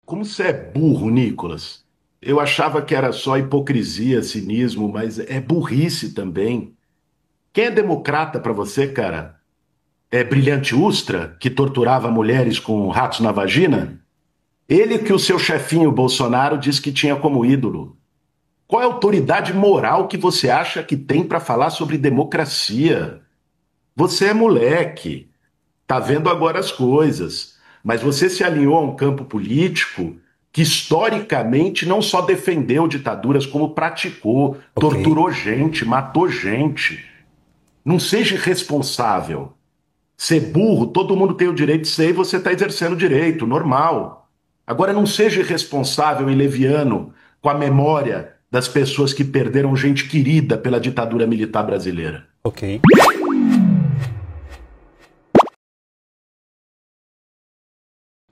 Durante debate promovido pela CNN Brasil na noite desta quinta-feira (28), o deputado Guilherme Boulos (PSOL-SP) chamou o colega Nikolas Ferreira (PL-MG) de “burro”. Boulos questionou a autoridade moral de Nikolas para falar sobre democracia, lembrando que ele se alinha a um campo político que historicamente defendeu ditaduras e praticou tortura durante o regime militar. O parlamentar ainda pediu que Ferreira não fosse “irresponsável ou leviano” com a memória das vítimas da ditadura.